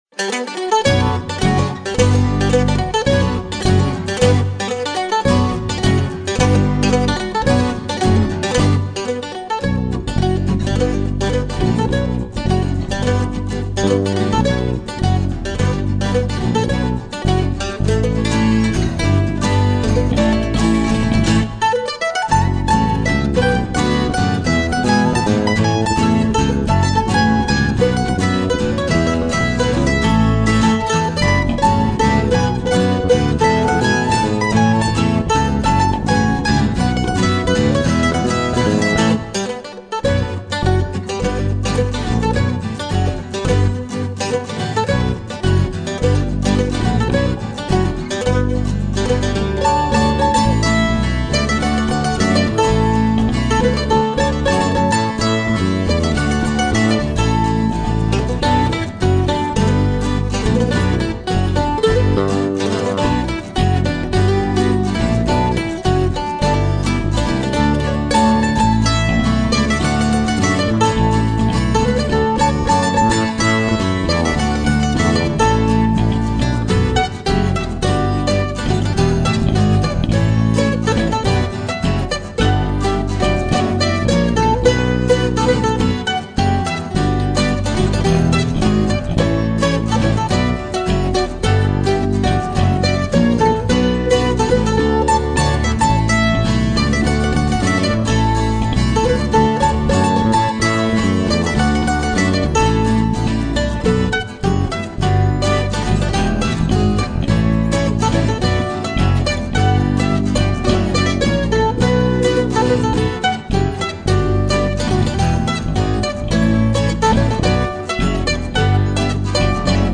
Zum Einstieg gibt’s erst mal ein stimmungsvolles Instrumental.